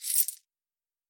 lootbox_win-Dvs12qZu.mp3